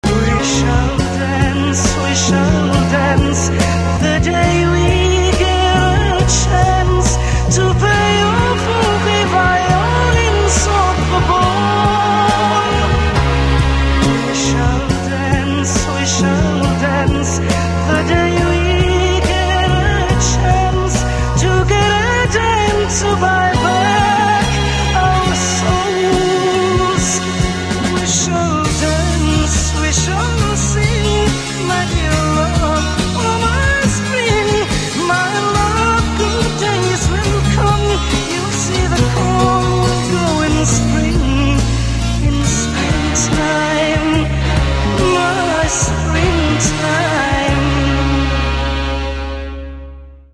Senza bassi, un vibrato stretto stretto...